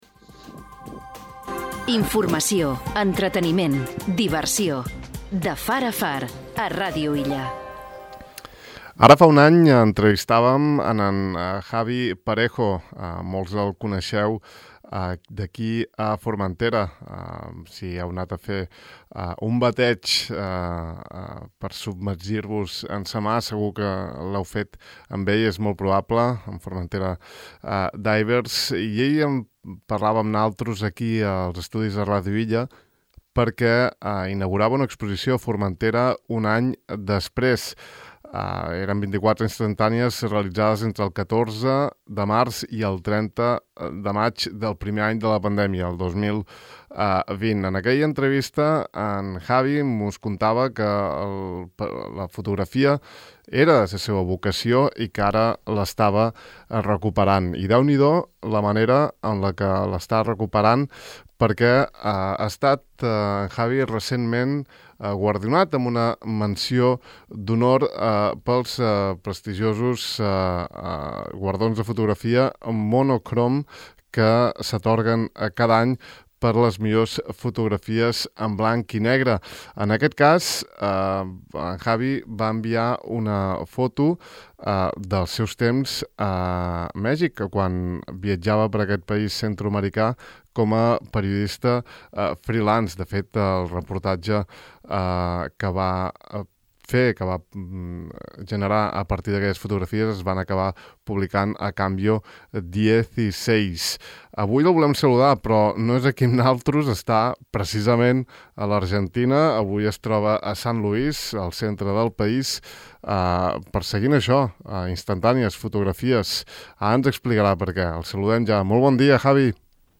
Podeu escoltar l’entrevista en aquest enllaç